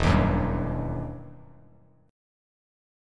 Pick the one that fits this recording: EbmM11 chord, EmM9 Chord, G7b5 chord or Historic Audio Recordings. EmM9 Chord